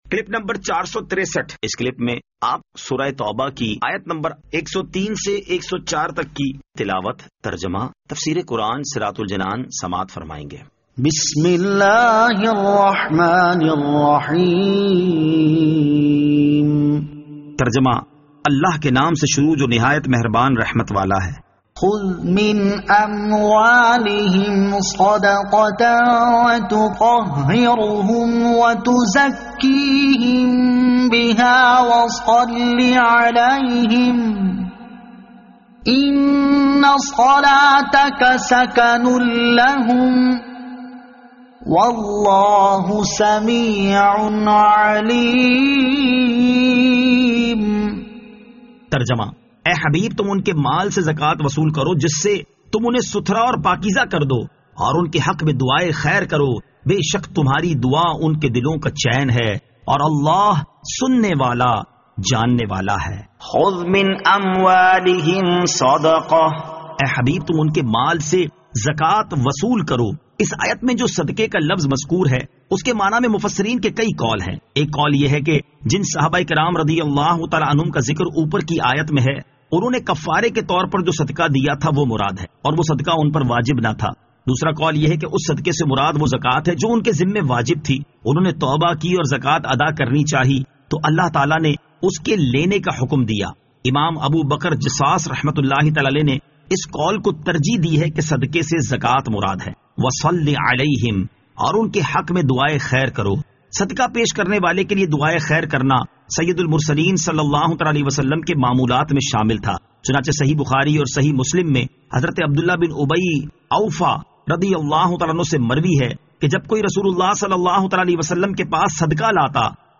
Surah At-Tawbah Ayat 103 To 104 Tilawat , Tarjama , Tafseer